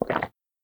EAT_Swallow_mono_2.ogg